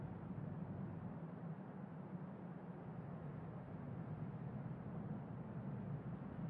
白噪声房间里2.wav